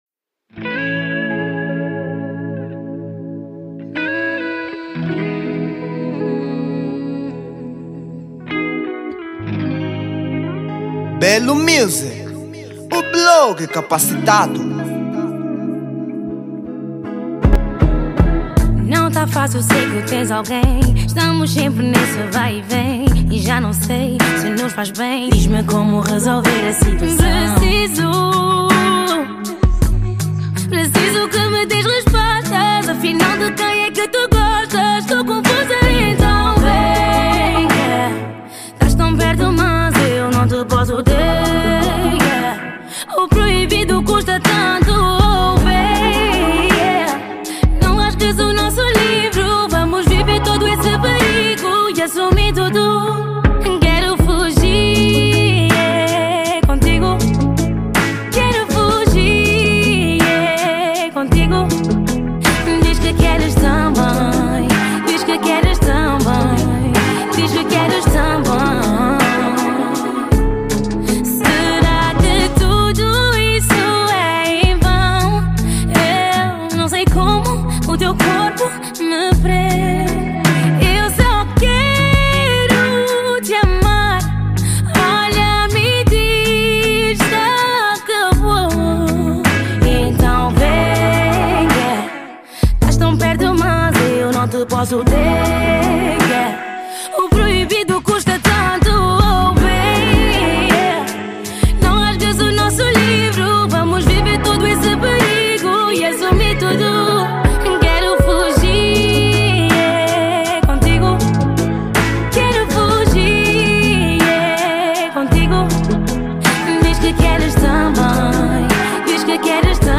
Género: R&b